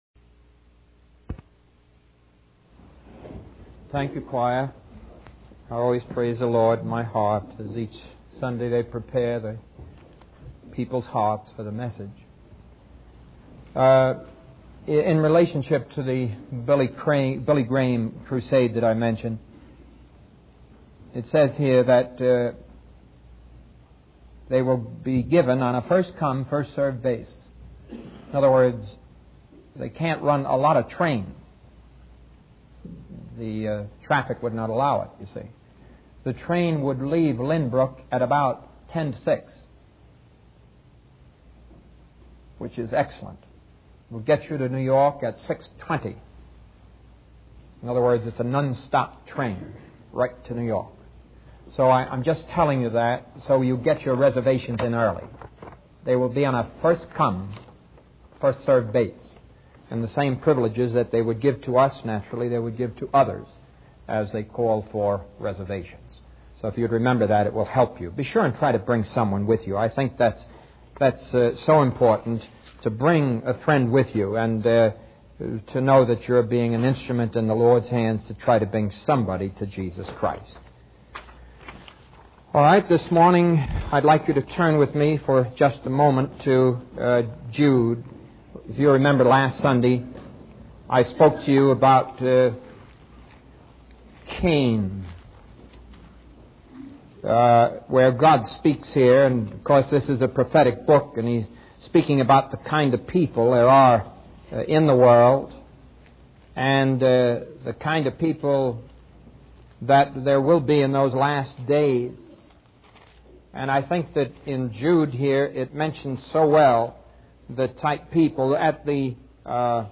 In this sermon, the preacher focuses on the story of Balaam and his talking donkey from the book of Numbers in the Bible.